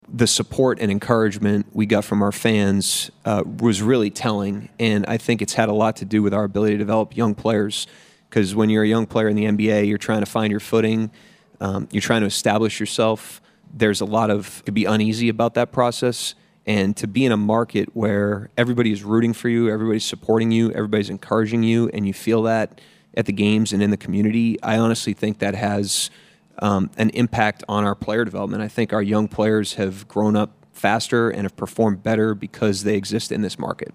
Head coach Mark Daigneault says the passionate fanbase makes player development a bit easier than other places.
Daigneault on OKC as a Market.mp3